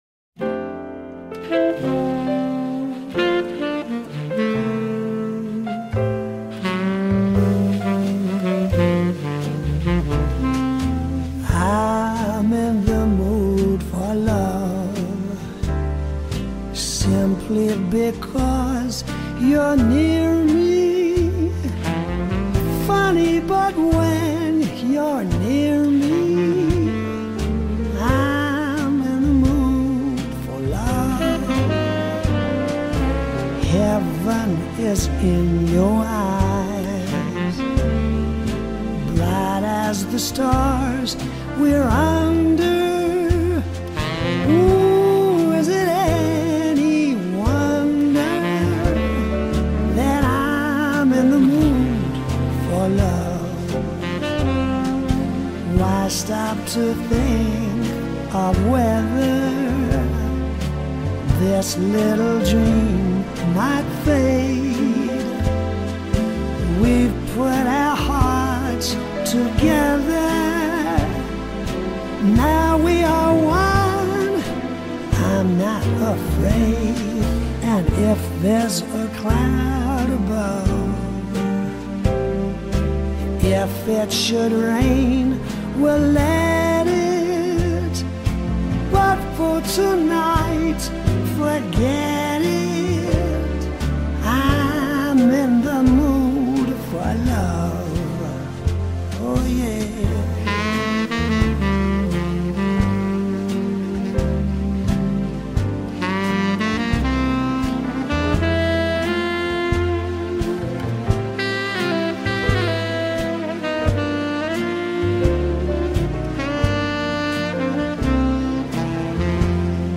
dal ritmo sufficientemente lento